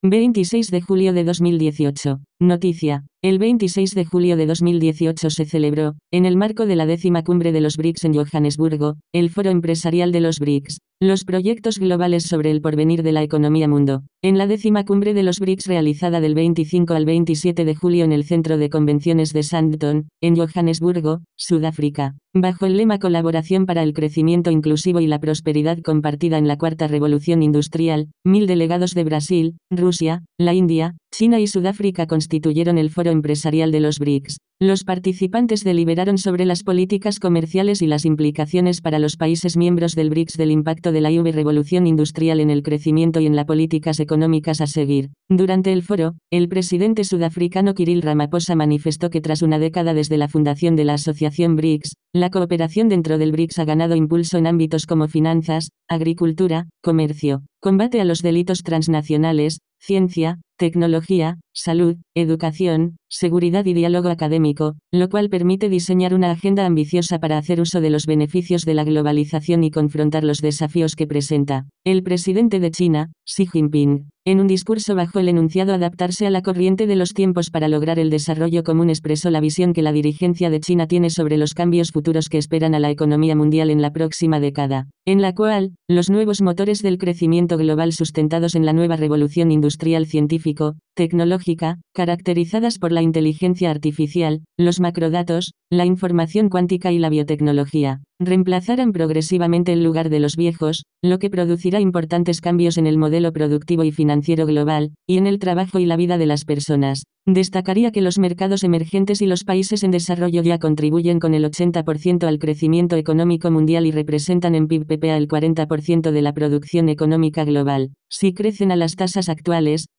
Lectura en voz alta